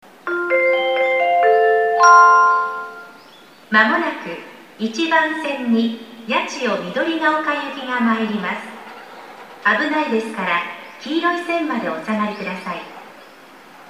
駅放送
2014年2月25日頃より接近メロディ付きの新放送に切り替わりました（到着放送は3月9日頃追加）。